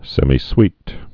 (sĕmē-swēt, sĕmī-)